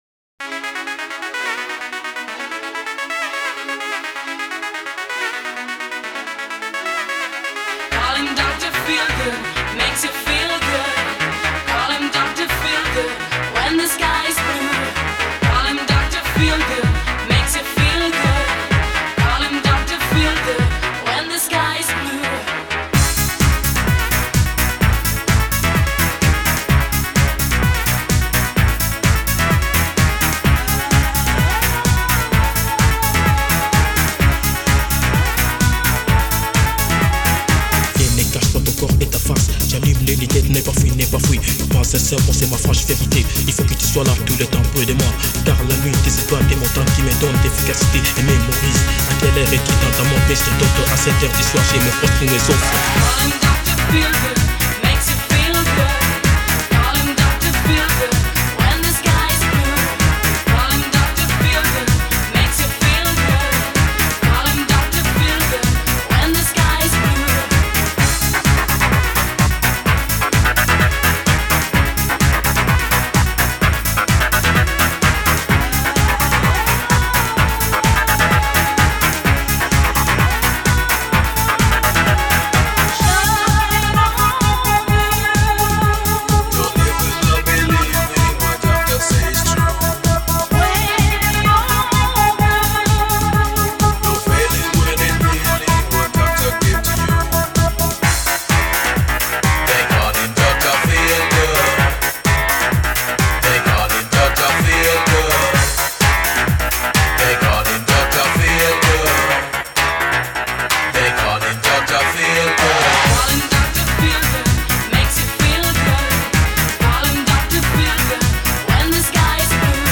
Классная музыка, релаксирует.